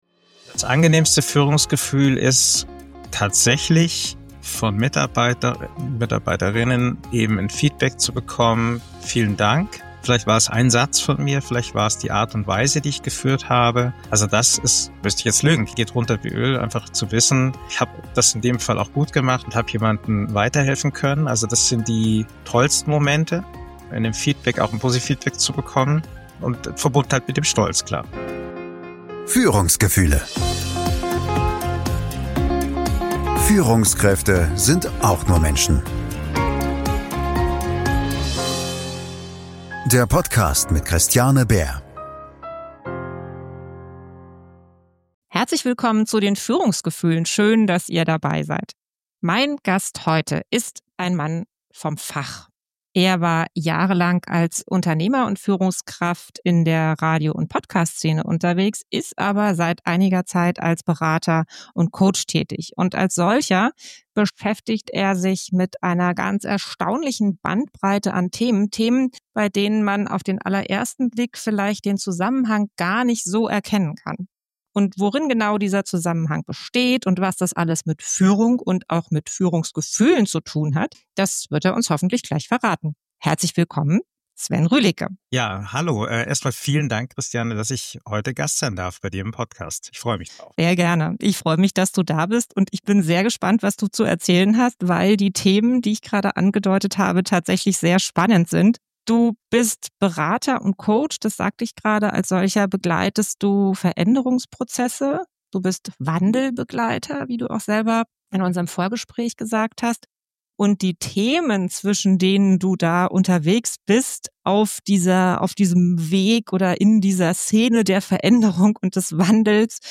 Raus aus dem Rausch – Warum Führung bei dir selbst beginnt - Gespräch